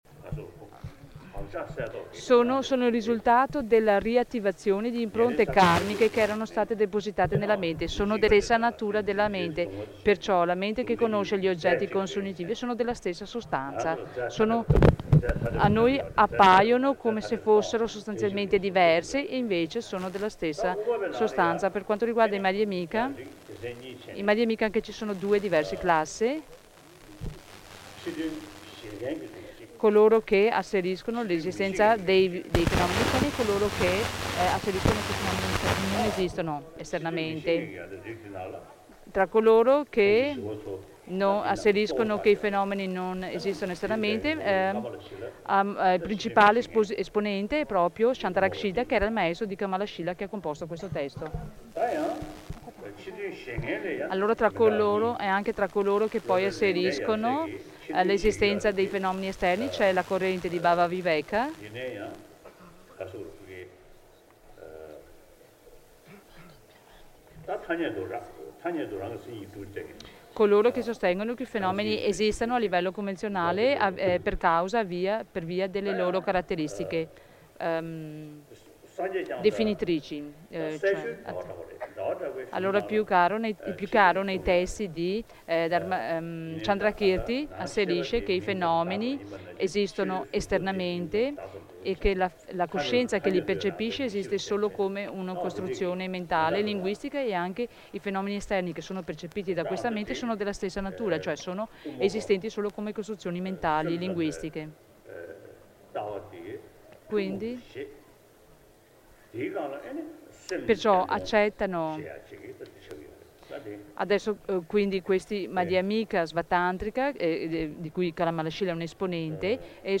Insegnamenti S.S. Dalai Lama Sarnath mattino 14.01.11